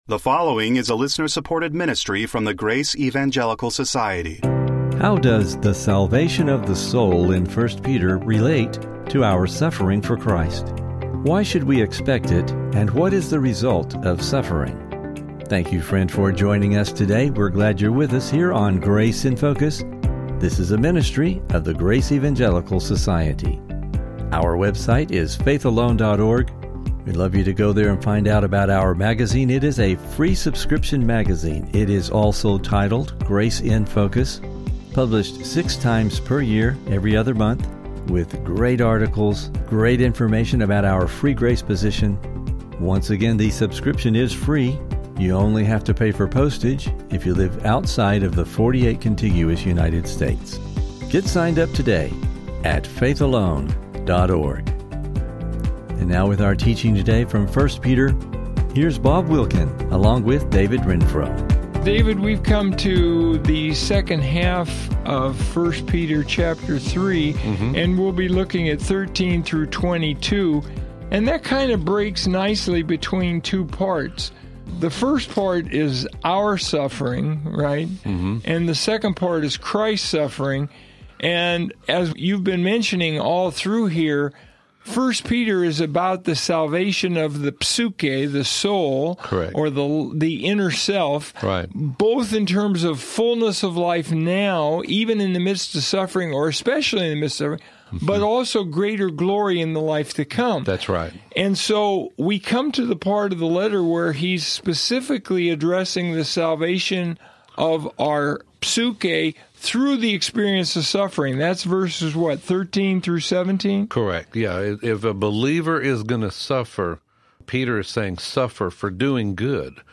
Please listen for a challenging discussion and never miss an episode of the Grace in Focus Podcast!